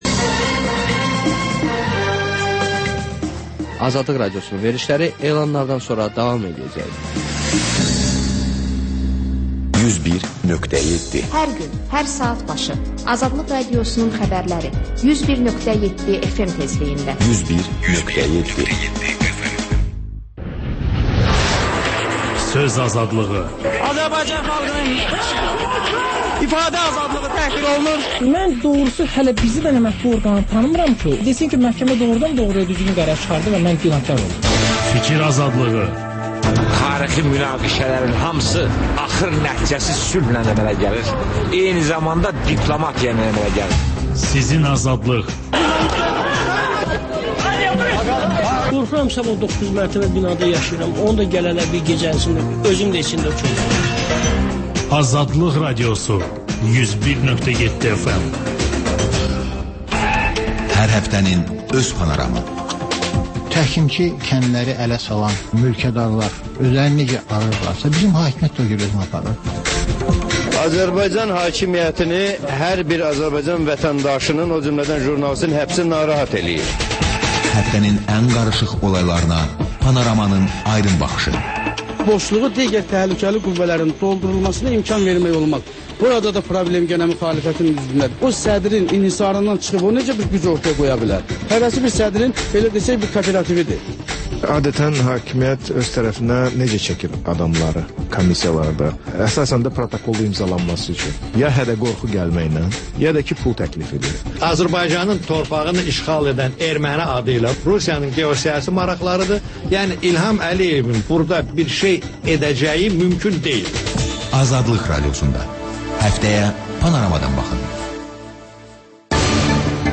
Xəbərlər, XÜSUSİ REPORTAJ: Ölkənin ictimai-siyasi həyatına dair müxbir araşdırmaları və TANINMIŞLAR rubrikası: Ölkənin tanınmış simaları ilə söhbət